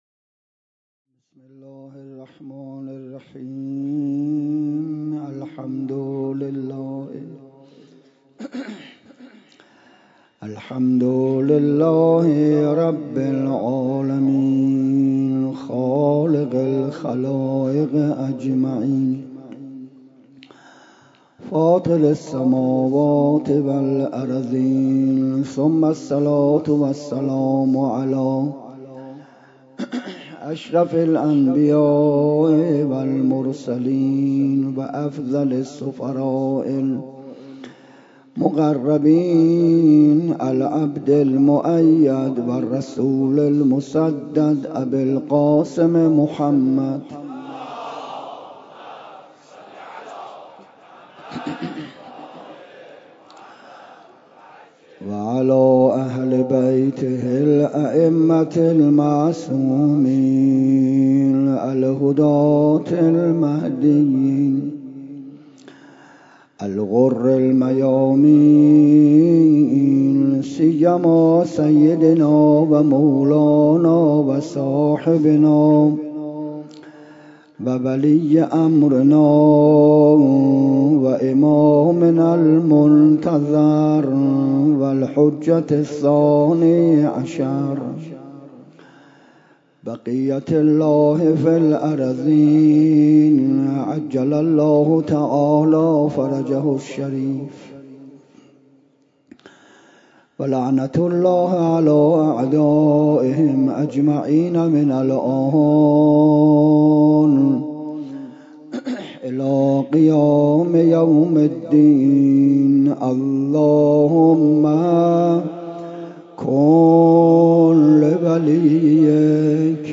شب اول محرم 97 - هیئت شبان القاسم - سابقه گریه و عزاداری بر سیدالشهدا سلام الله علیه